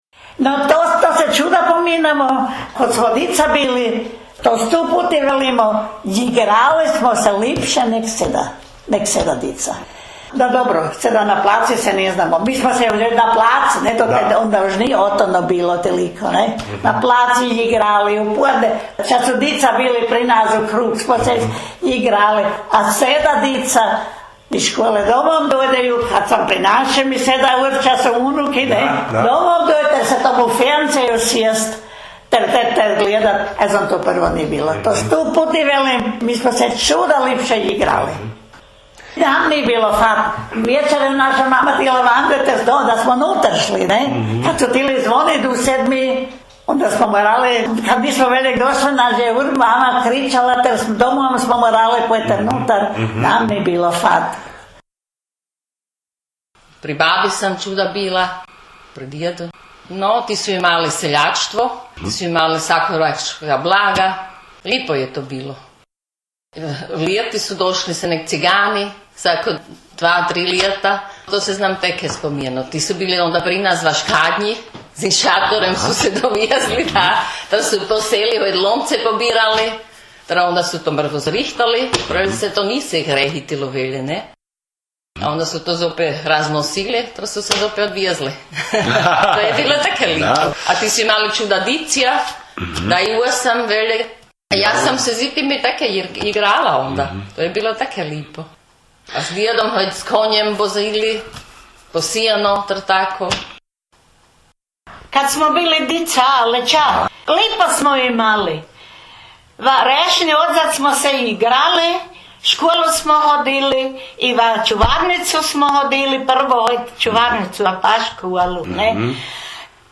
jezik naš, jezik naš gh dijalekti
Gijeca – Govor